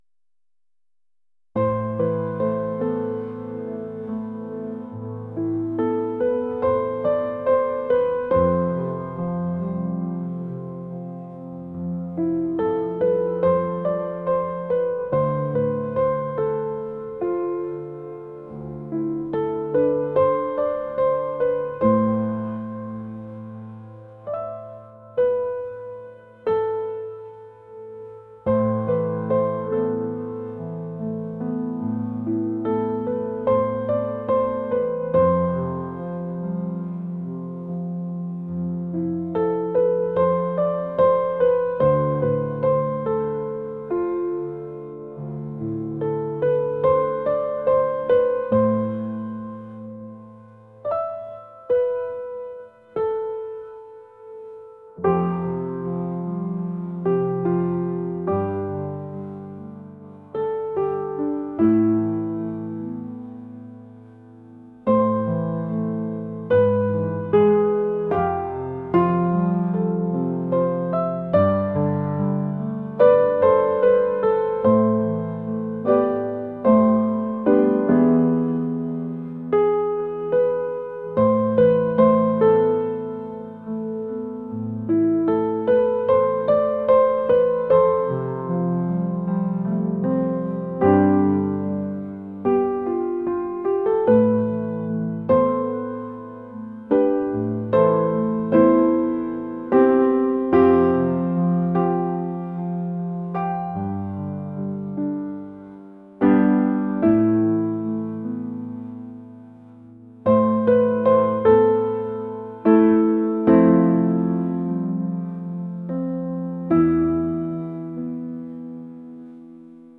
pop | atmospheric